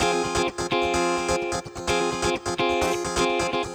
VEH3 Electric Guitar Kit 1 128BPM
VEH3 Electric Guitar Kit 1 - 18 F min.wav